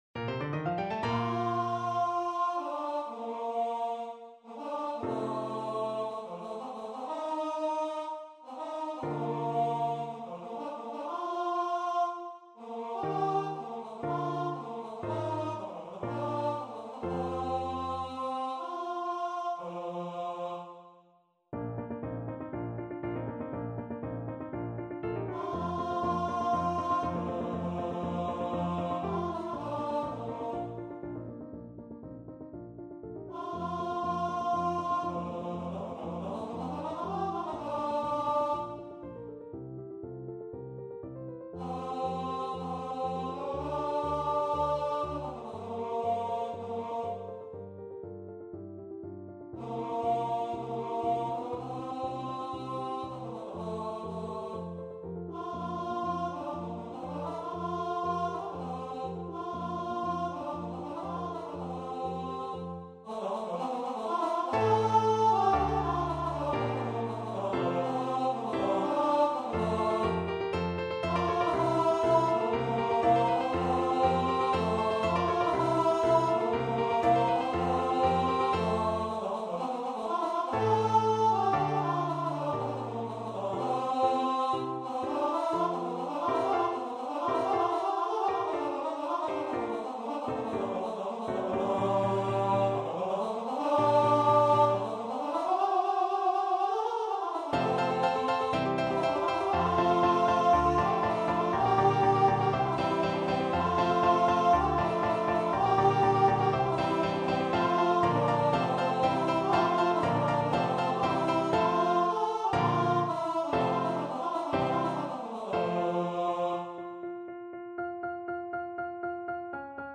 ~ = 100 =60 Maestoso
4/4 (View more 4/4 Music)
Classical (View more Classical Tenor Voice Music)